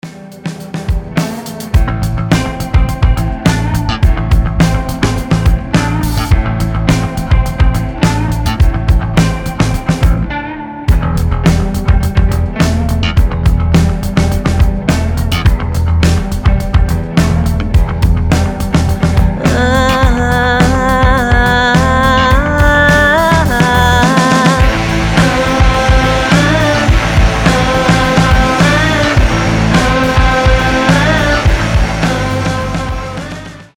• Качество: 320, Stereo
гитара
без слов
Alternative Rock
Стильная рок-музыка на звонок